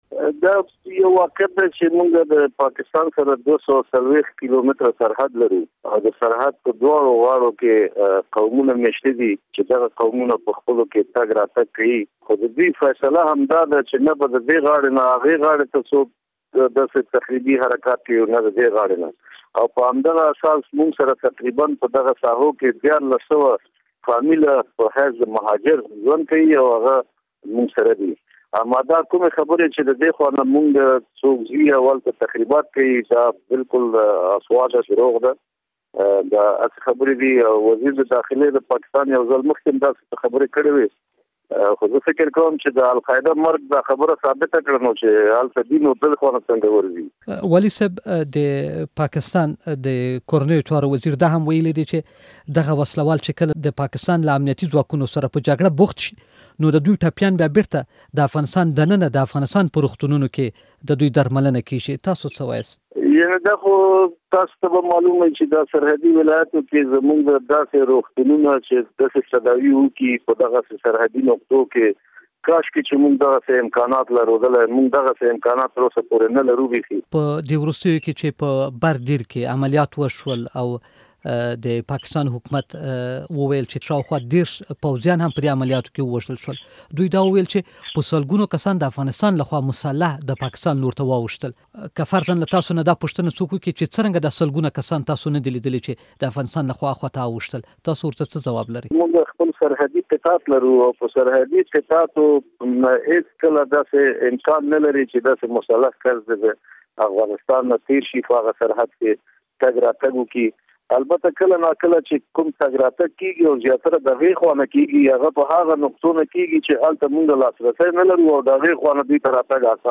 له سید فضل الله واحدي سره مرکه